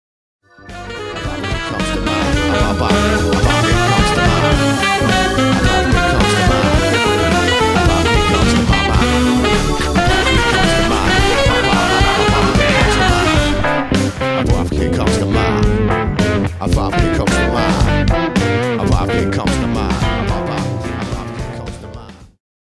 Heads Up - Remix - Explorations in Improvised Music
I wrote this piece around a back-cycling symmetrical dominant approach to a 12 bar blues.